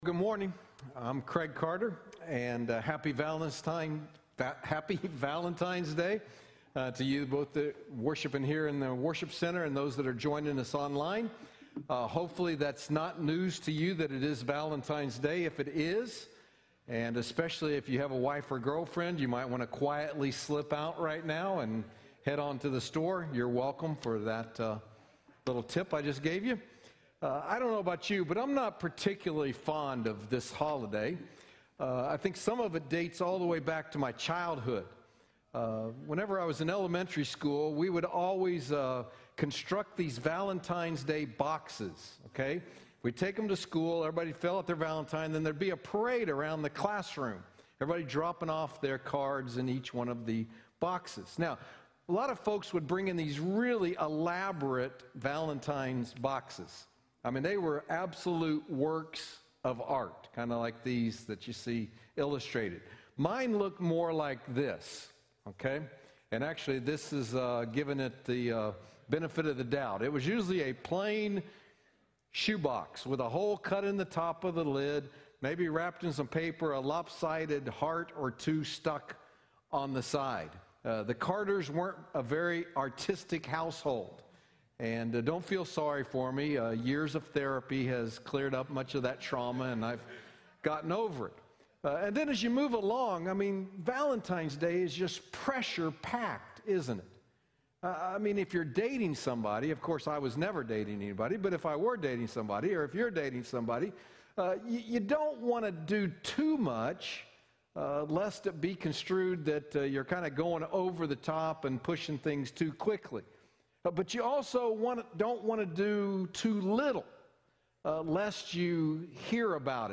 Sacred 4-Letter Words Service Type: Sunday Morning Download Files Notes Bulletin « Sacred 4-Letter Words
Sermon-Audio-2.14.21.mp3